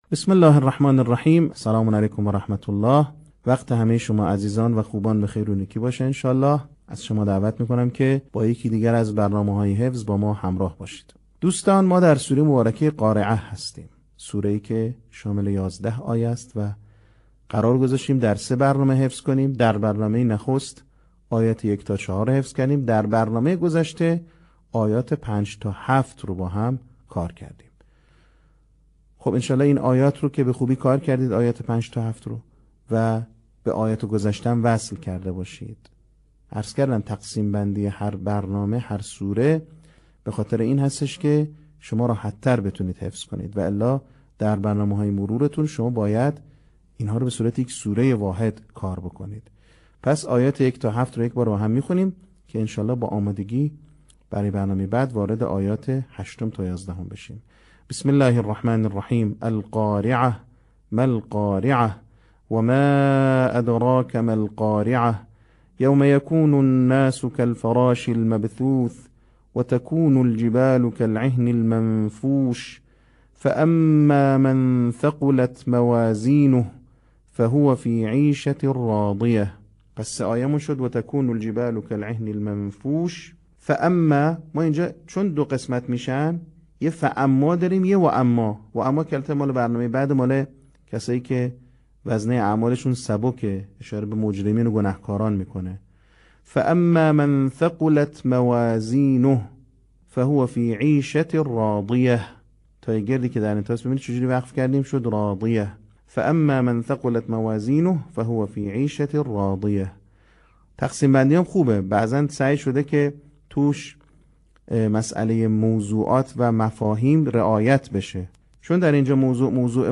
صوت | بخش چهارم آموزش حفظ سوره قارعه
به همین منظور مجموعه آموزشی شنیداری (صوتی) قرآنی را گردآوری و برای علاقه‌مندان بازنشر می‌کند.